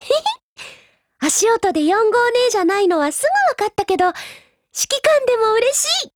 贡献 ） 协议：Copyright，其他分类： 分类:少女前线:UMP9 、 分类:语音 您不可以覆盖此文件。
UMP9Mod_DIALOGUE2_JP.wav